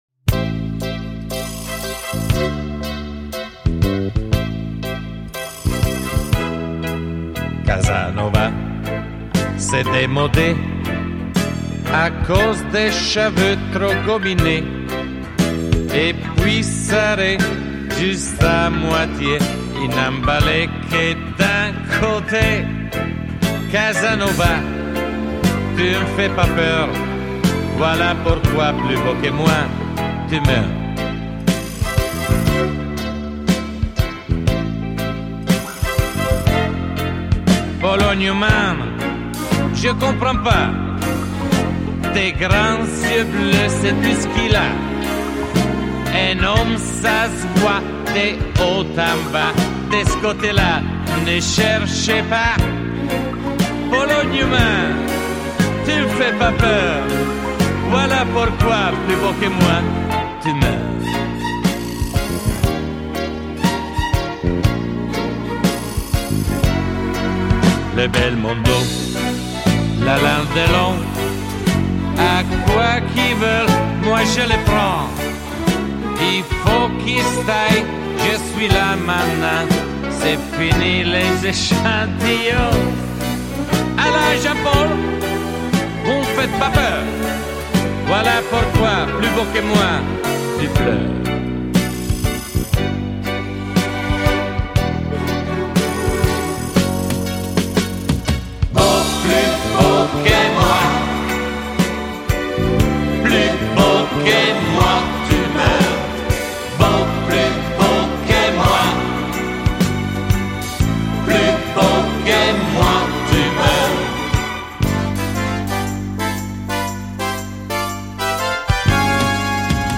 Et le son est top.